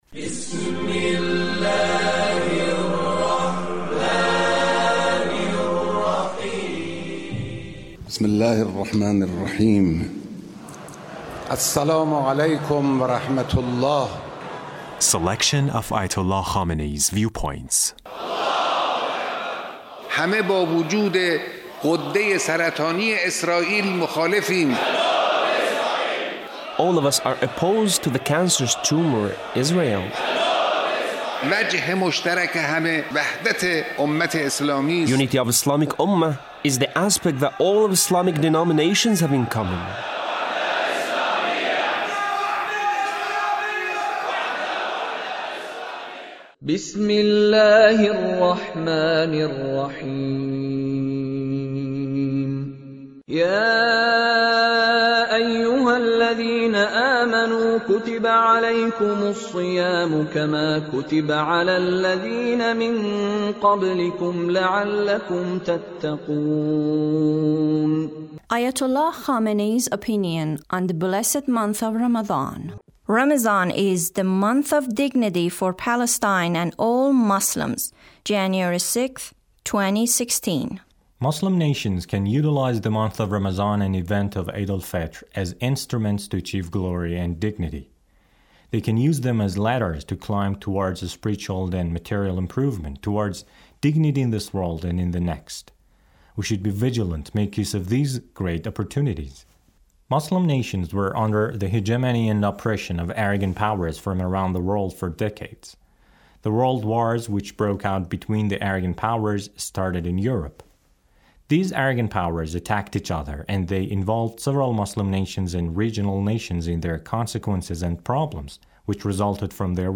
Leader's speech (20)